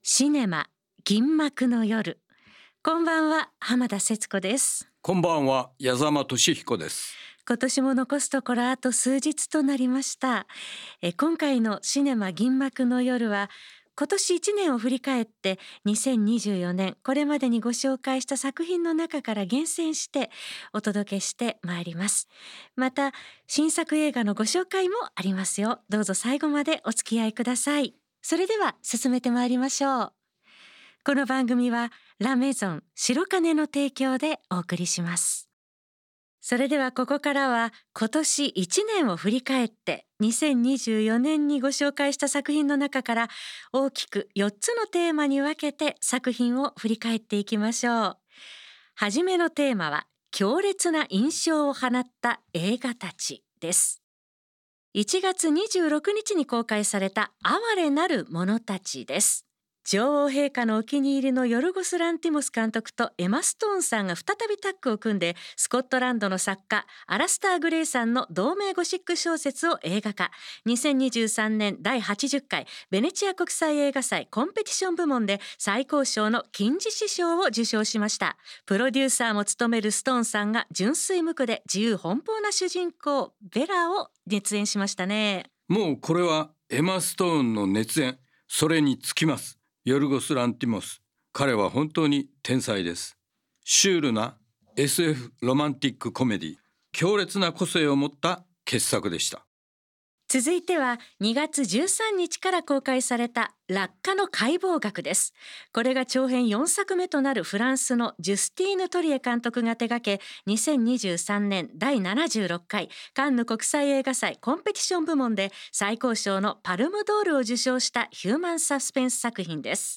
最新の映画情報と過去の名作映画を音楽と共に紹介する30分。